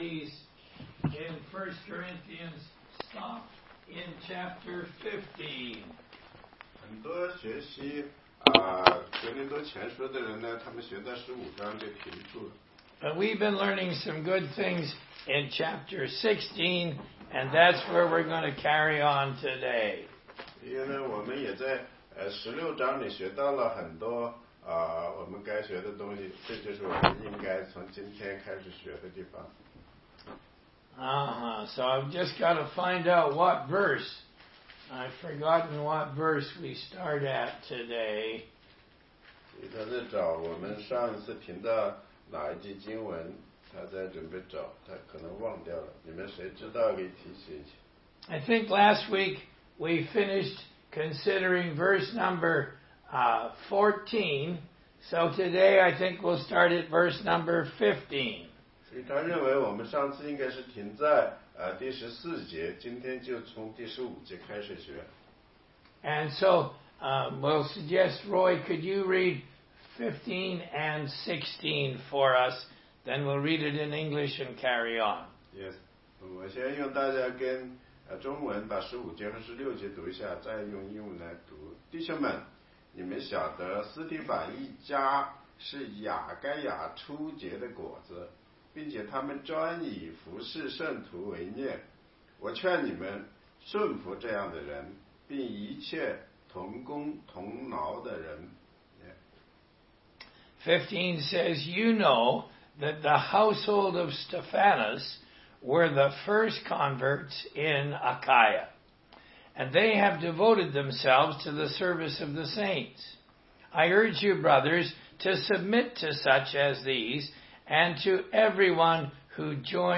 16街讲道录音 - 哥林多前书16章15-24节：若有人不爱主，这人可诅可咒